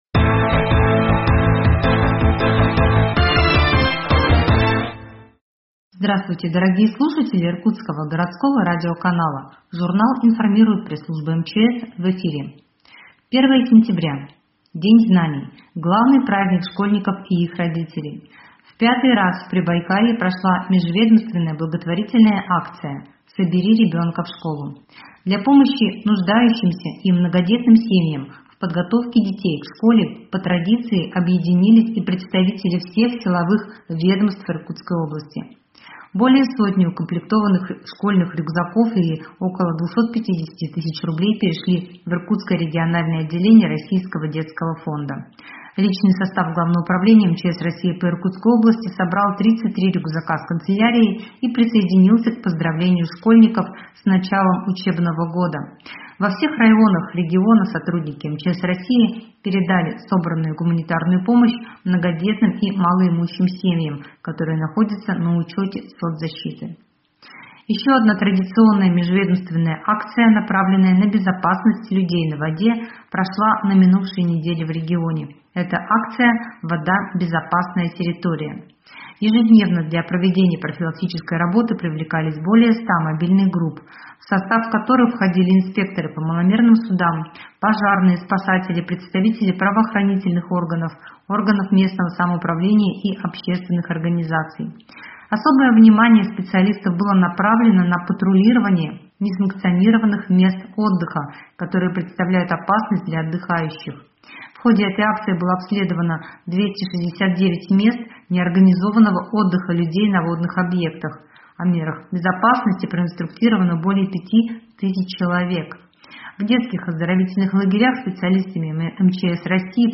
Еженедельная пятничная рубрика ГУ МЧС России по Иркутской области.